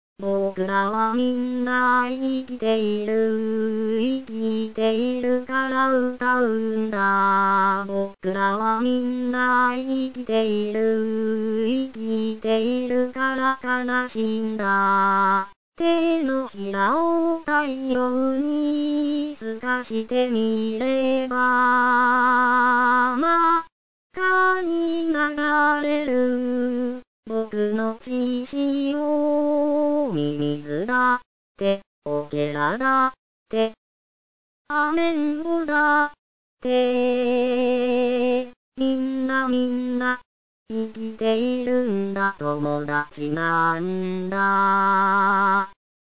アカペラを聴く